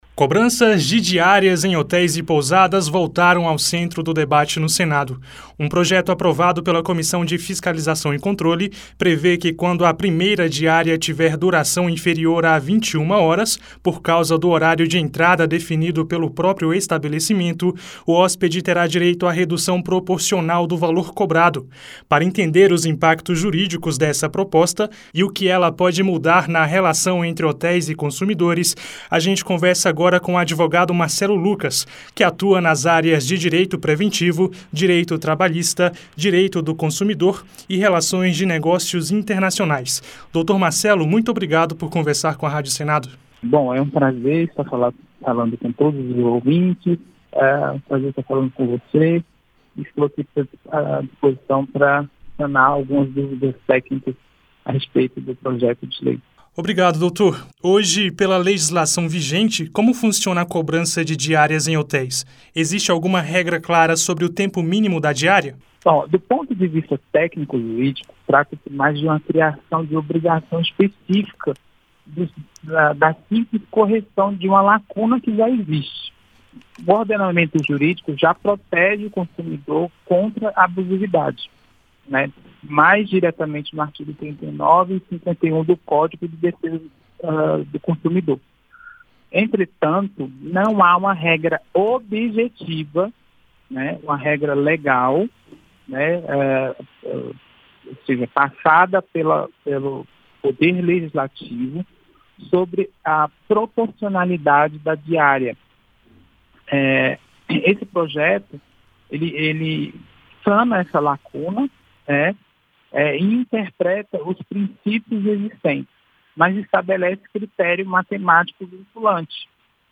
Na Íntegra